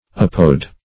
Apod \Ap"od\, Apode \Ap"ode\, n.; pl. Apodsor Apodes. [Gr.